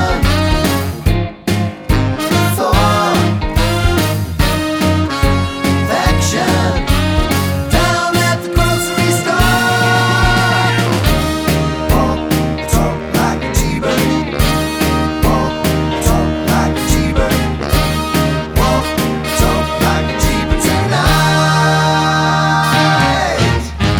No Backing Vocals Soundtracks 3:06 Buy £1.50